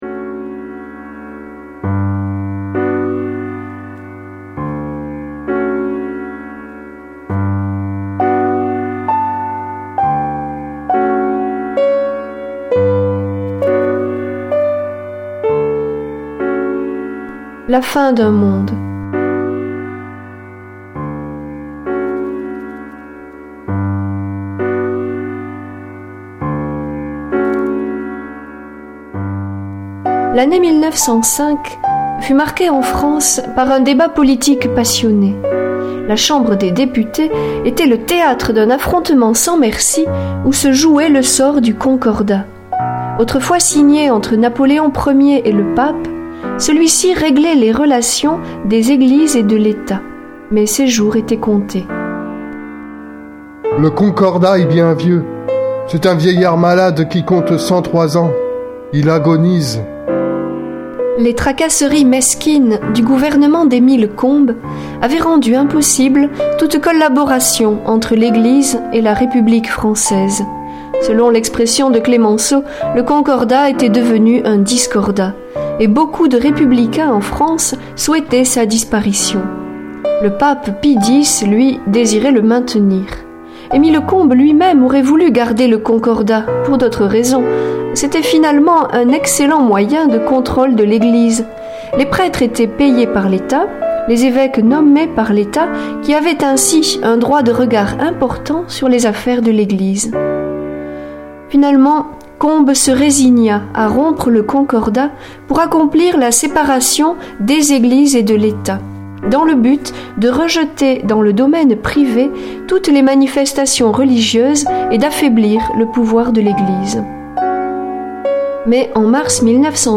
Enseignement
Récits vivants et riches balayant les grands épisodes de l'Histoire de France.